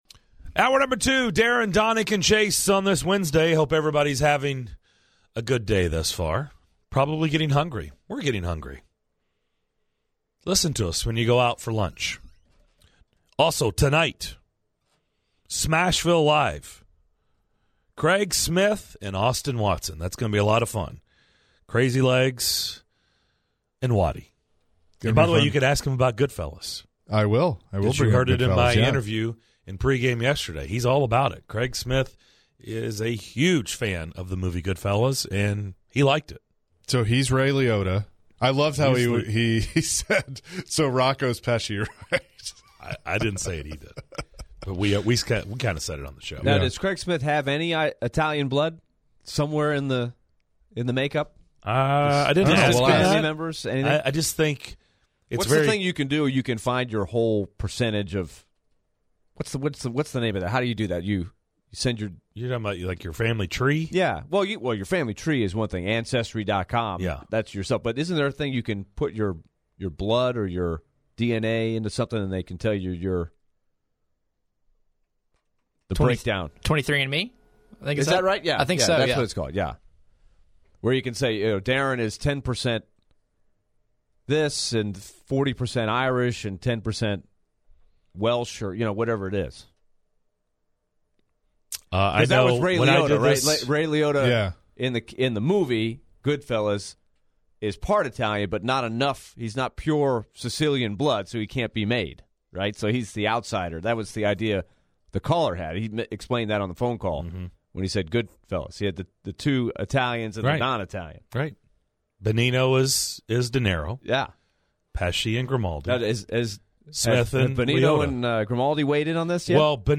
In the second hour of Wednesday's DDC: the guys debate whether or not the Titans should pursue Tom Brady and recap the Vanderbilt loss to Tennessee in men's hoops.